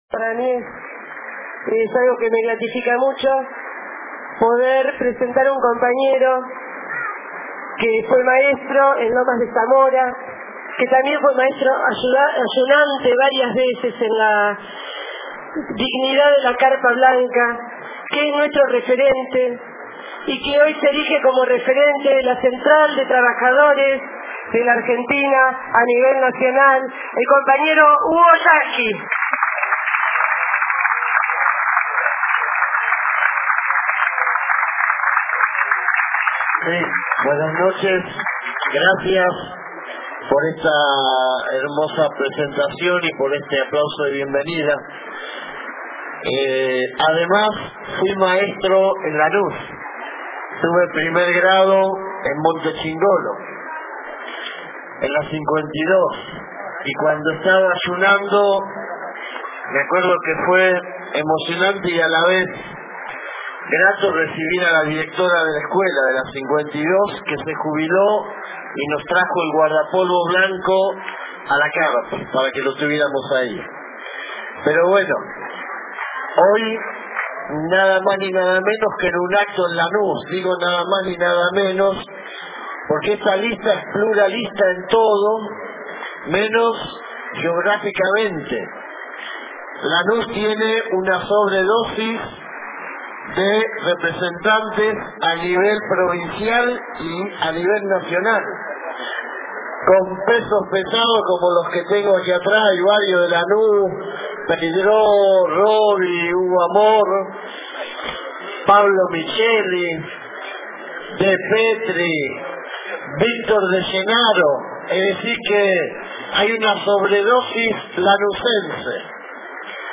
DISCURSO HUGO YASKY EN LANUS 27 OCTUBRE 2006 (MP3 - 1.3 MB)
Pese a un corte de luz propio de un sabotaje el acto de presentaci�n de la Lista 1, Germ�n Abdala-Lanus, se desarroll� en la calle con el entusiasmo y la participaci�n masiva de los trabajadores y trabajadoras que se dieron cita para participar de esta fiesta democr�tica.